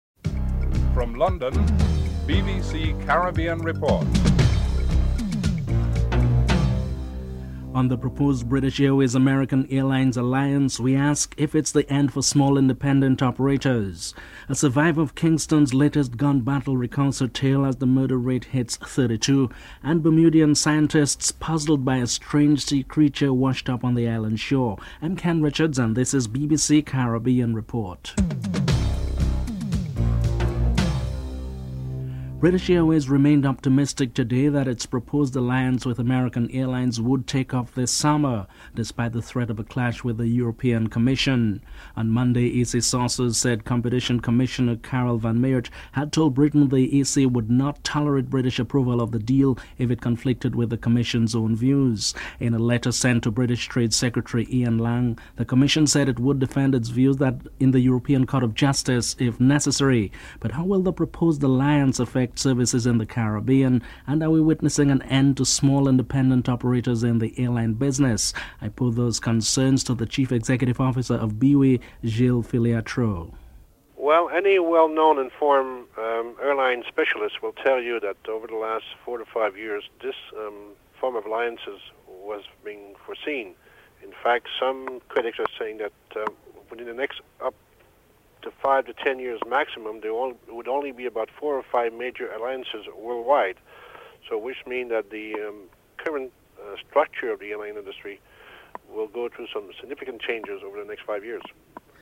1. Headlines (00:00-00:27)
3. Gang violence in Jamaica. Interviews with both Edward Seaga, Opposition Leader and Derrick Smith, Opposition Jamaica Labour Party spokesman on crime (03:54-07:08)